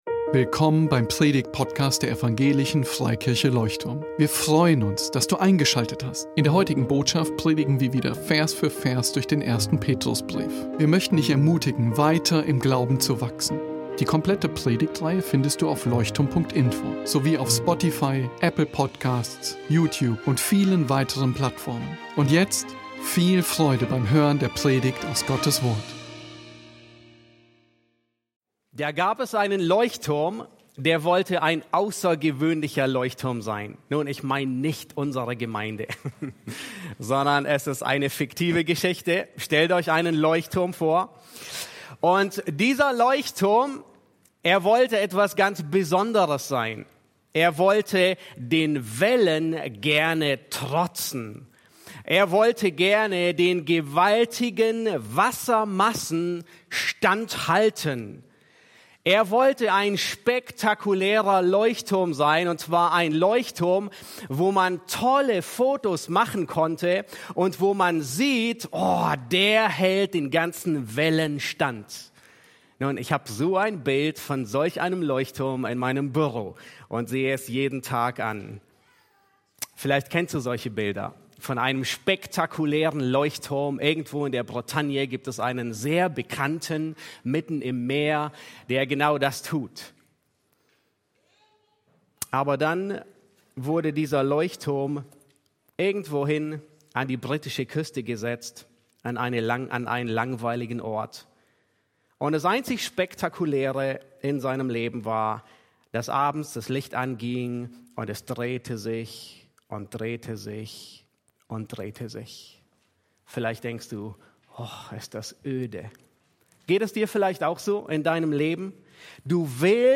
Leuchtturm Predigtpodcast